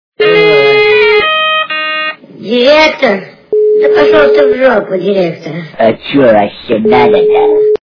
» Звуки » Смешные » C большого бодуна - Директор?... да пошел ты в жопу Директор... А че вообще надо то?
При прослушивании C большого бодуна - Директор?... да пошел ты в жопу Директор... А че вообще надо то? качество понижено и присутствуют гудки.